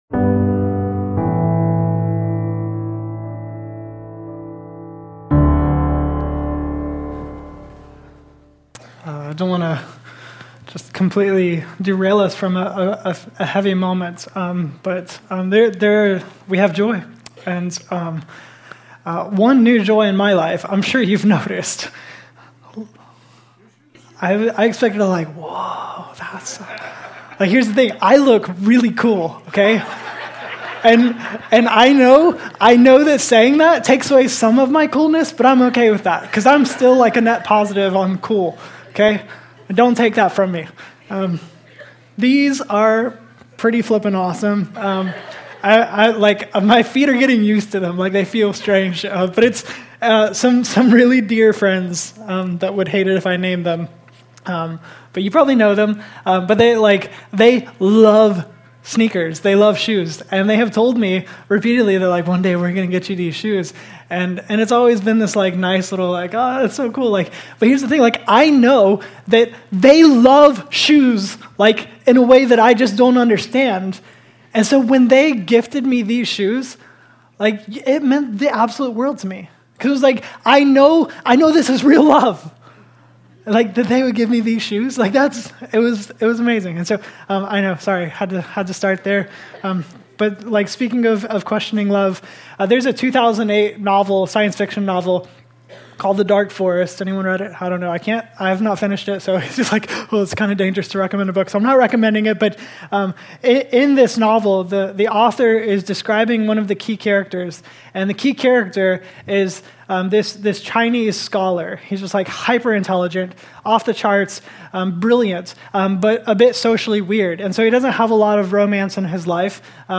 and our neighbor in this week’s sermon